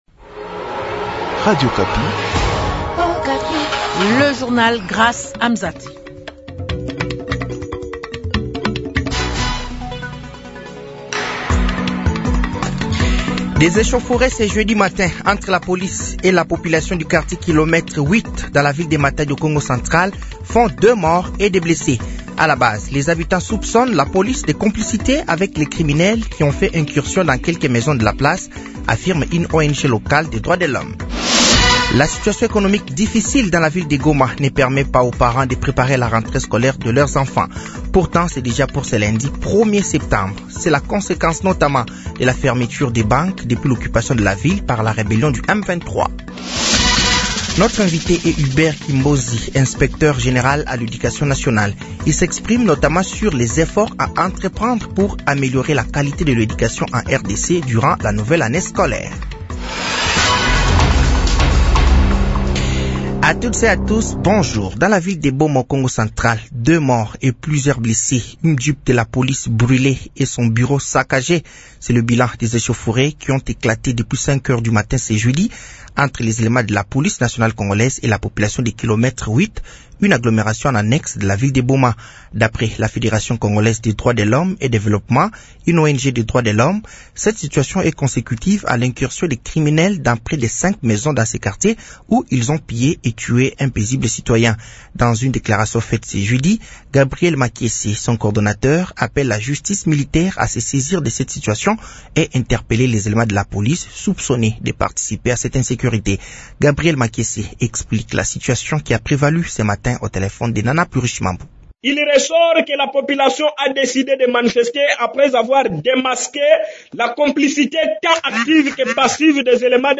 Journal français de 15h de ce jeudi 28 août 2025